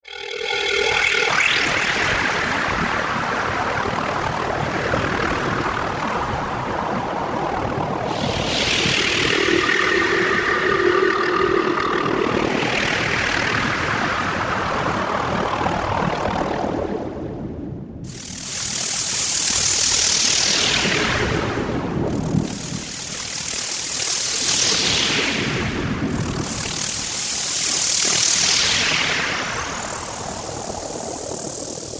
Tag: 120 bpm Ambient Loops Fx Loops 2.69 MB wav Key : Unknown